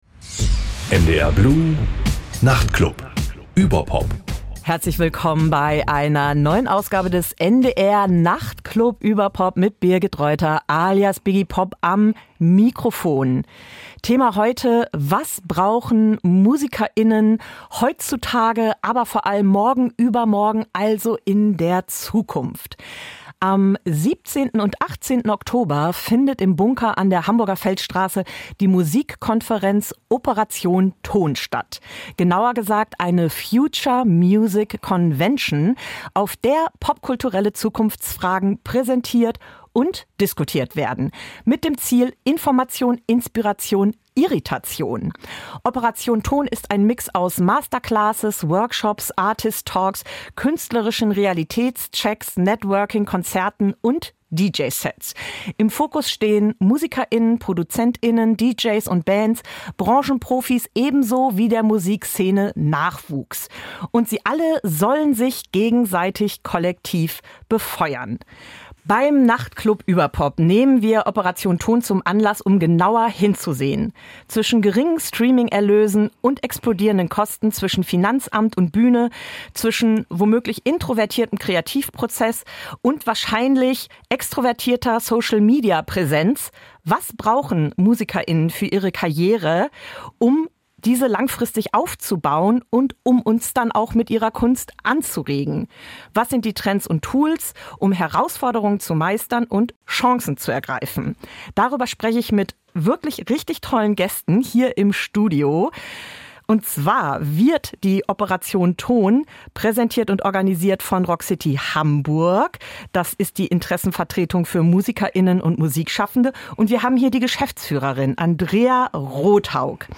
Die Profis im Studio geben praxisnahe Antworten und anregende Einblicke in den künstlerischen Alltag.